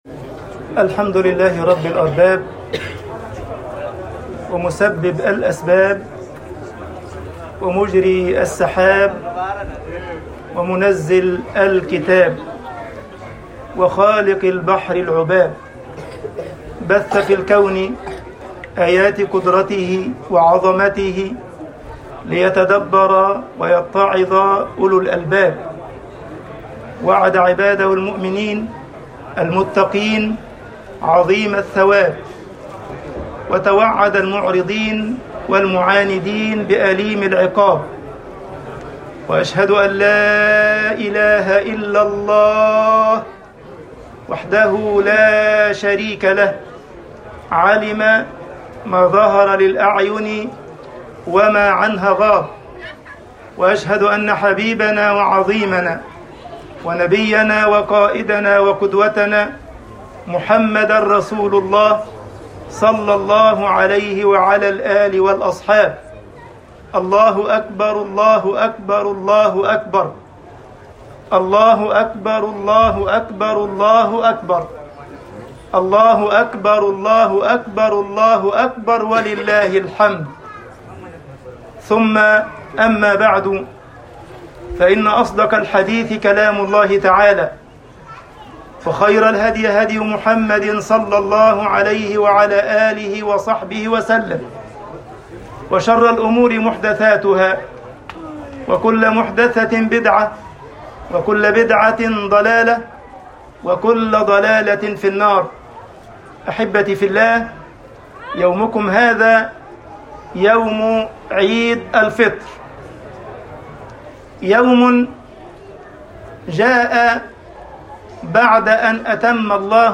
خُطْبَةُ عِيدِ الْفِطْرِ1443ه
خطب الجمعة والعيد